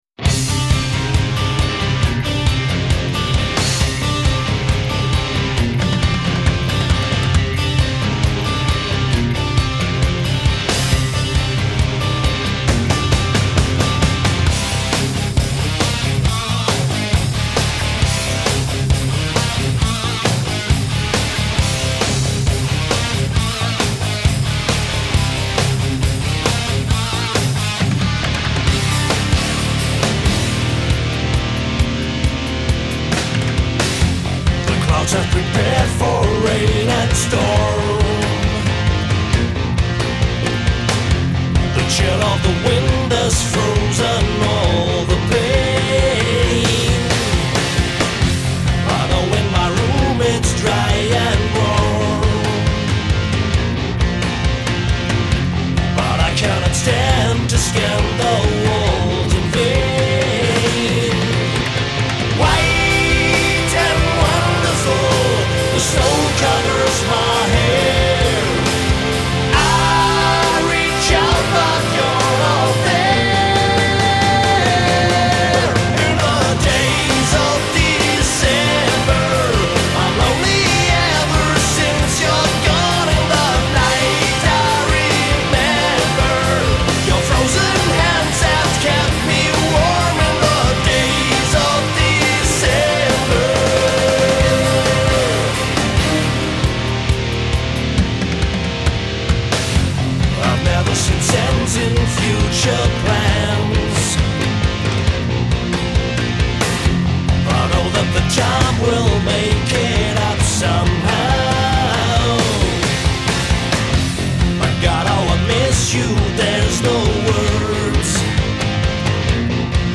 1.  Soaring clear melodic vocals.
2.  Thundering double drum kicks
3.  Melodic fast lead guitar.